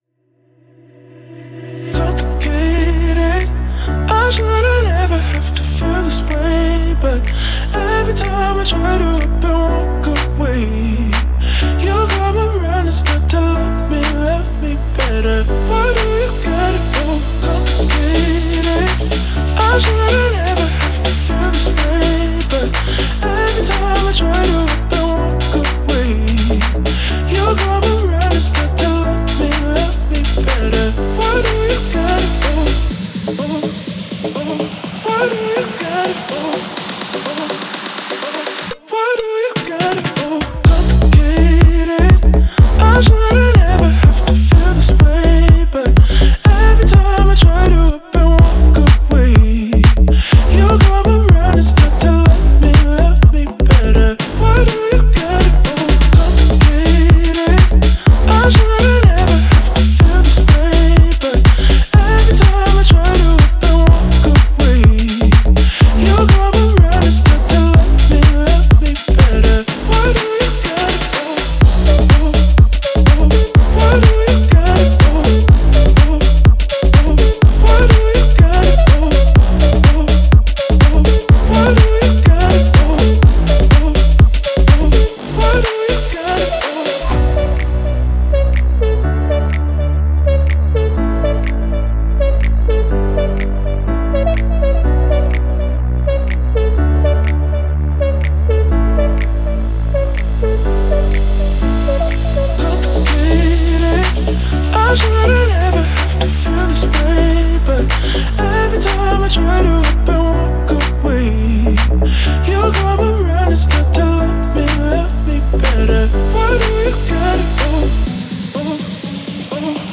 зажигательная поп-электронная композиция